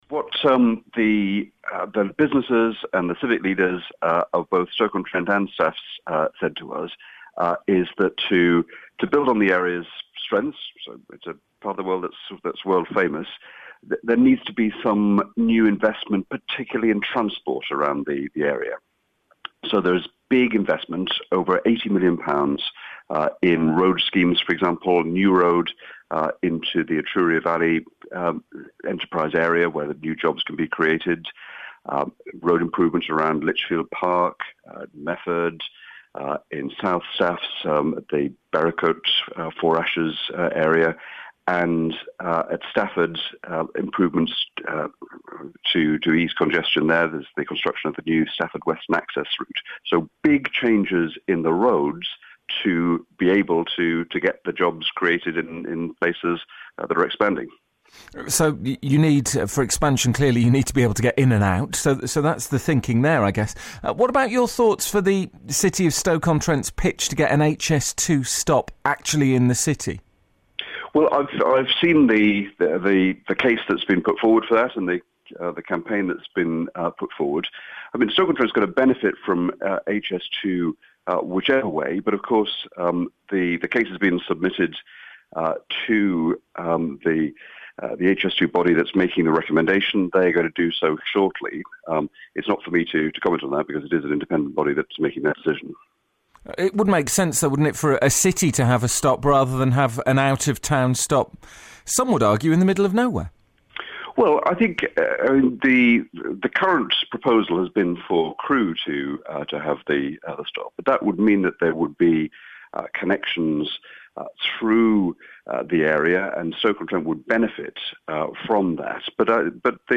Greg Clark interview